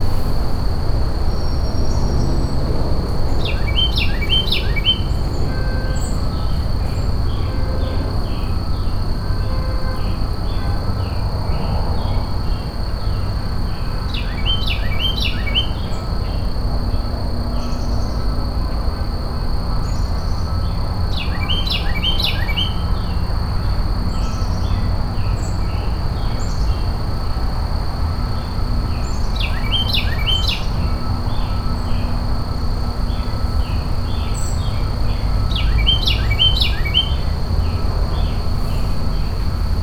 Now, I don’t have a recording of the song of the Passer domesticus or any of the other aforementioned, but I do have a short recording of one of the “loudest songs per volume,” the song of the Thryothorus ludovicianus (Carolina Wren).